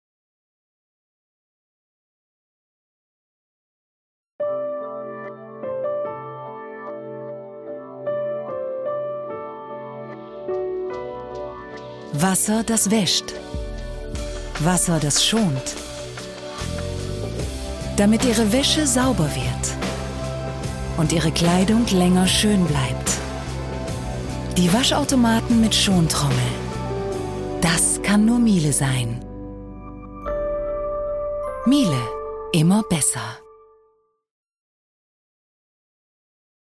,deutsche Sprecherin, mittlere-dunkle Klangfarbe, Sprecherin fuer Funk u. Fernsehen,Internet, cd-rom,documentary,voice-response,Kinderhoerbuecher
Sprechprobe: Werbung (Muttersprache):
german voice-over, med-dark voicetimbre working for TV-and radio ,internet,documentaries,voice-response,childrenbooks, 2nd language:dutch (not native)